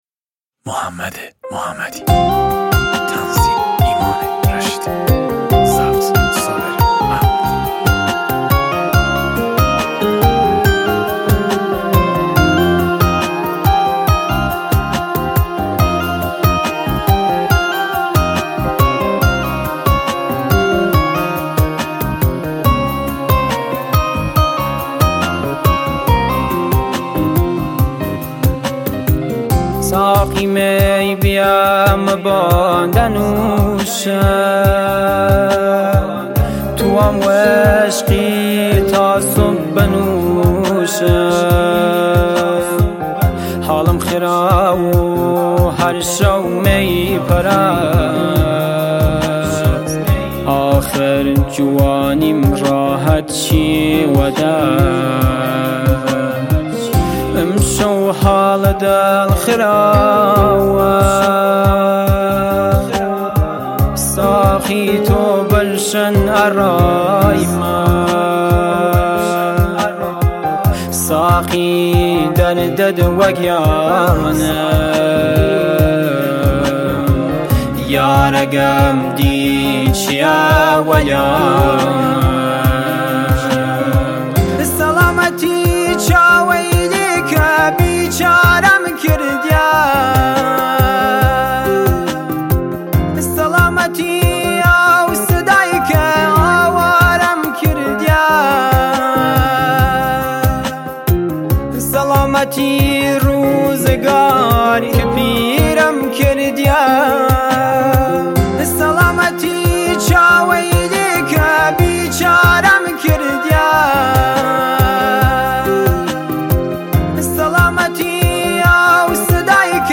آهنگ کردی و سنندجی